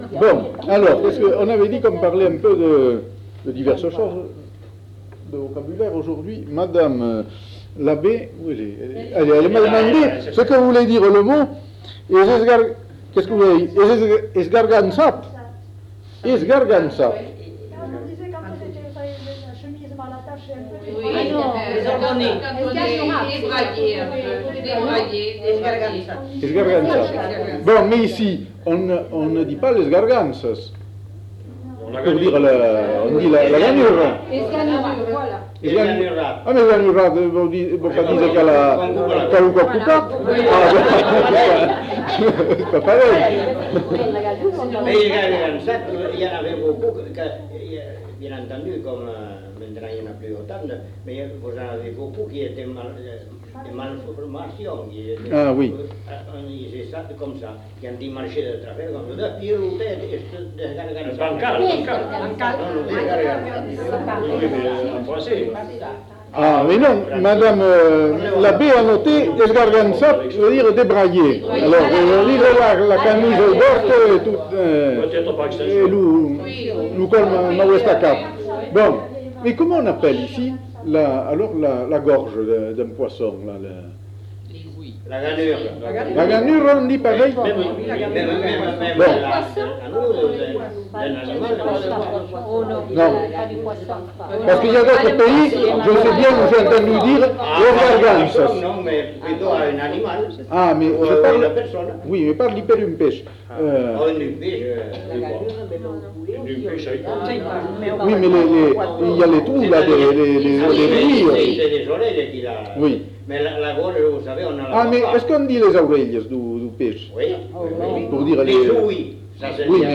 Discussion sur les pratiques de guérison